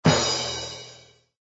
SZ_MM_cymbal.ogg